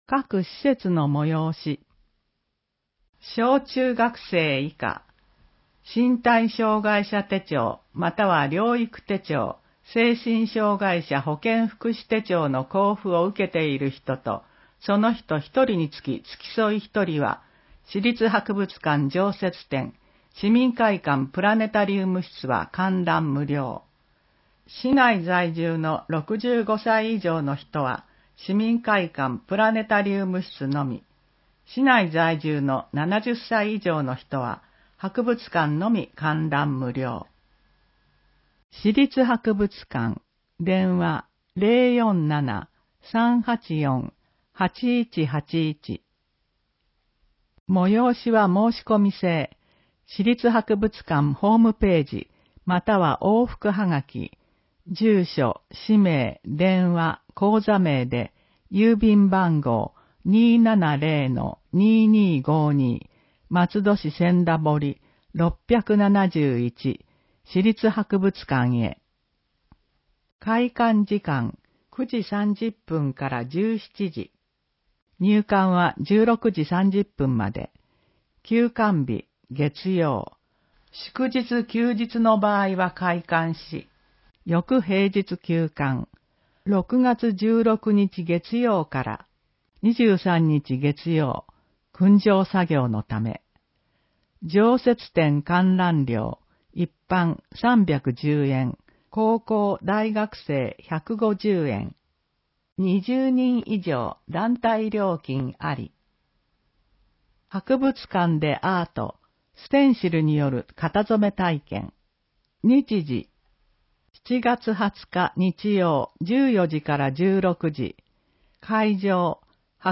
松戸朗読奉仕会のご協力で、広報まつどの音声版を公開しています。